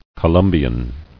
[Co·lum·bi·an]